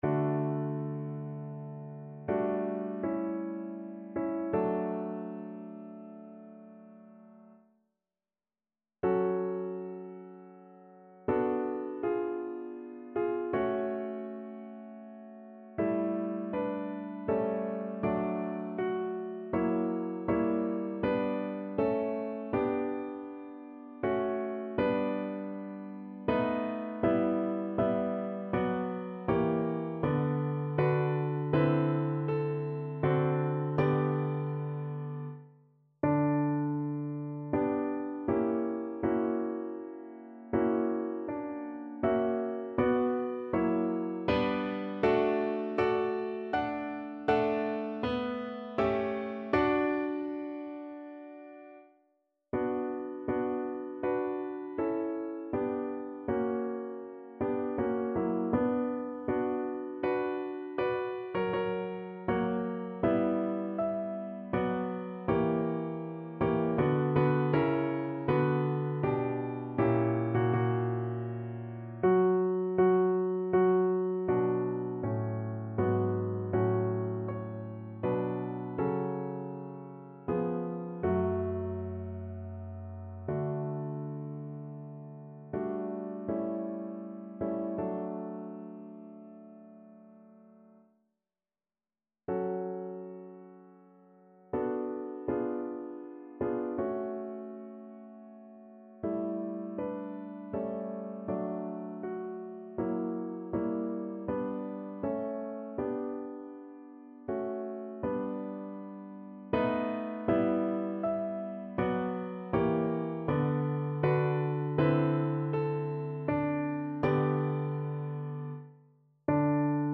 No parts available for this pieces as it is for solo piano.
3/4 (View more 3/4 Music)
~ = 80 Andante ma non lento
Piano  (View more Intermediate Piano Music)
Classical (View more Classical Piano Music)